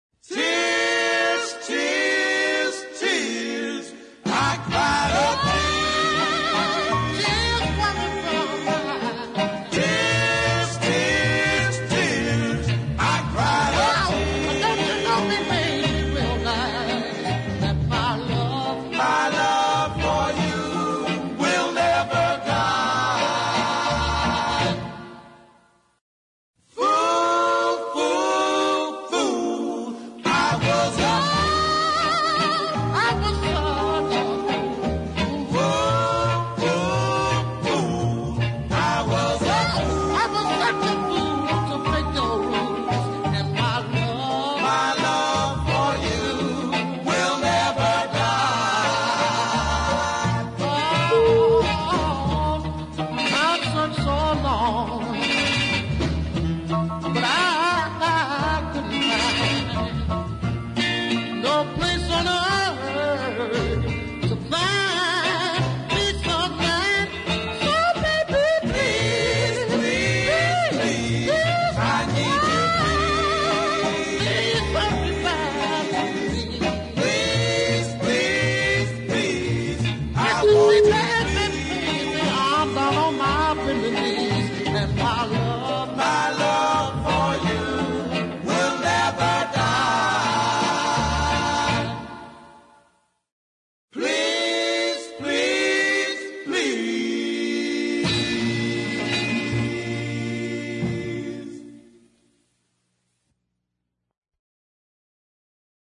R & B slowie
wailed like banshee, with some exhilarating falsetto bursts.